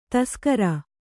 ♪ taskara